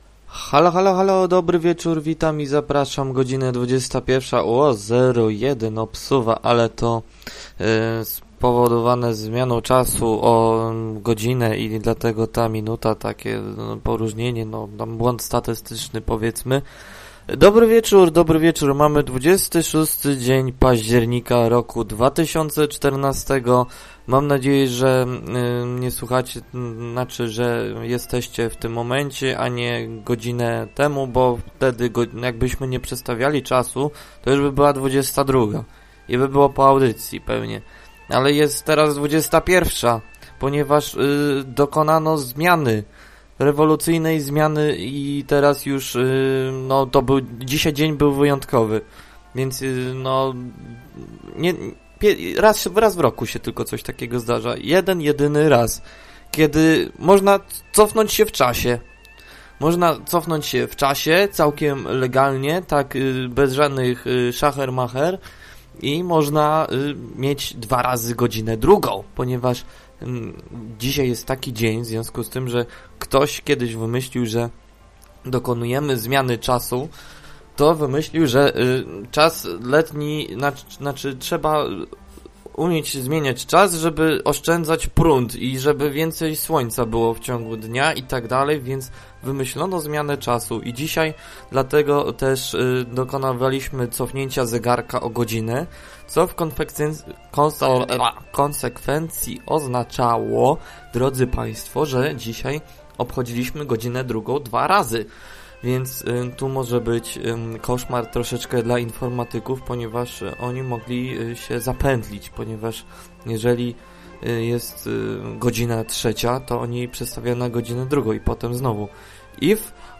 Problemy techniczne zaczynają się robić tradycją...